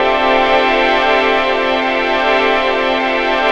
Index of /90_sSampleCDs/Best Service ProSamples vol.10 - House [AKAI] 1CD/Partition C/PADS